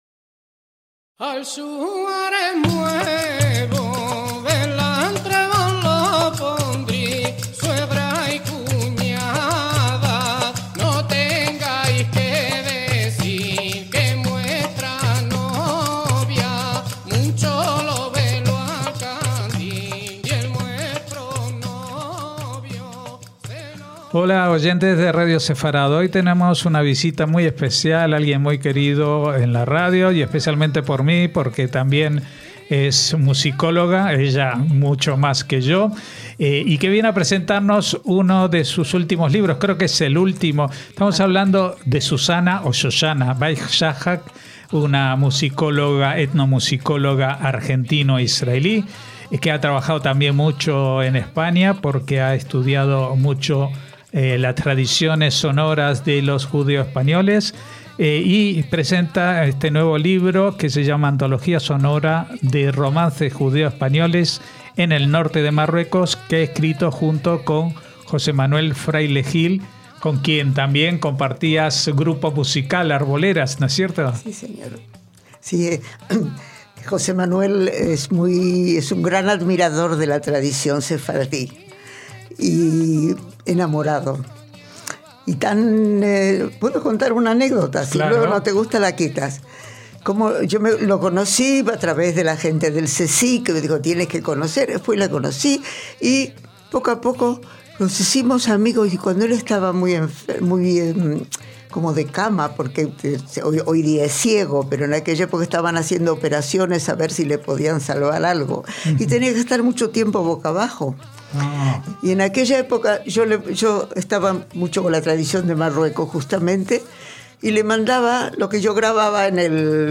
Antología sonora de romances judeoespañoles en el norte de Marruecos